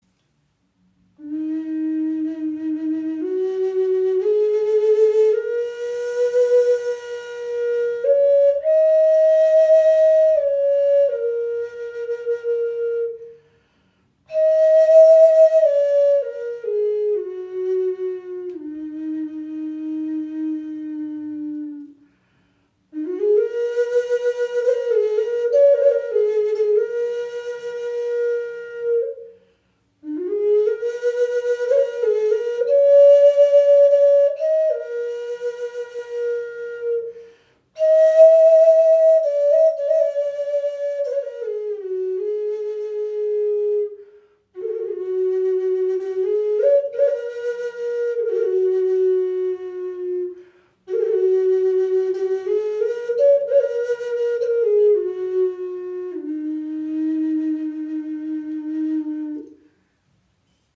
Okarina aus Wurzelstück | E4 in 432 Hz | Pentatonische Stimmung | ca. 24 cm
Klein, handlich, klangvoll – eine Okarina mit Seele
In pentatonischer E4 Moll Stimmung auf 432 Hz gestimmt, entfaltet sie einen warmen, klaren Klang, der Herz und Seele berührt.
Trotz ihrer handlichen Grösse erzeugt sie einen angenehm tiefen und warmen Klang – fast ebenbürtig zur nordamerikanischen Gebetsflöte.